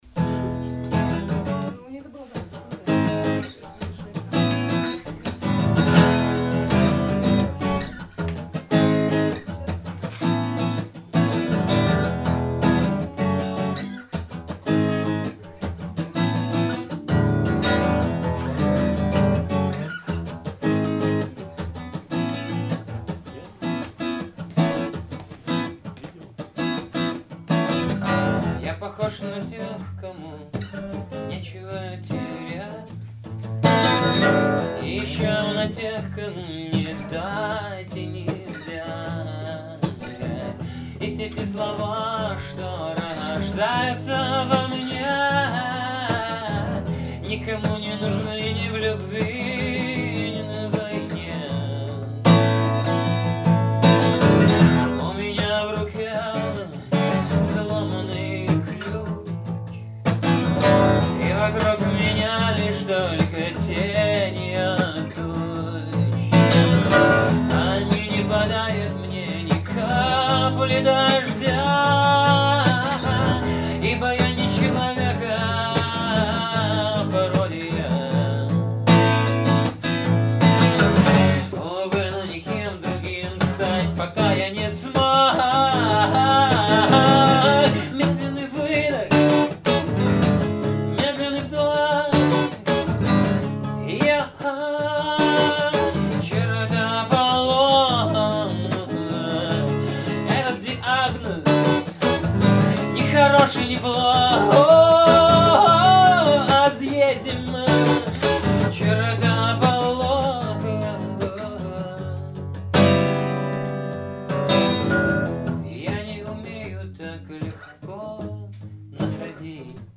Жанр: Singer.